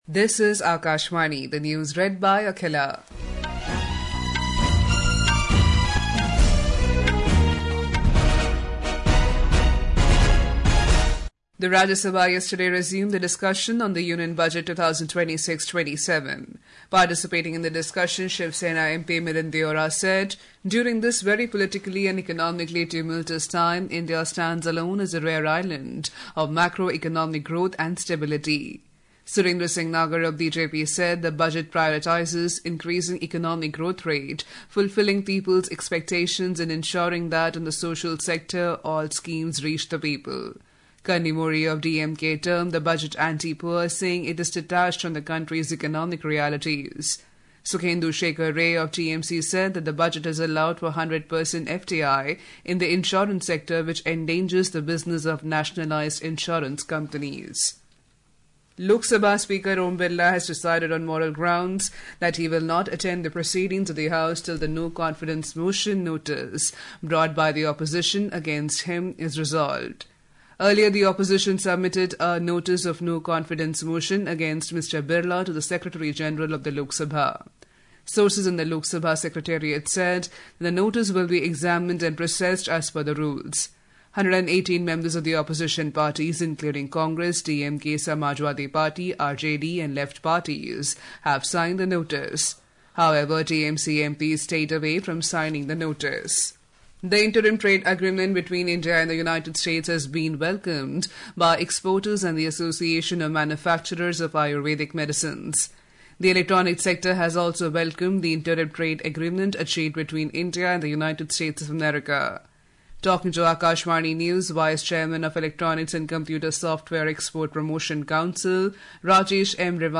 National Bulletins
Hourly News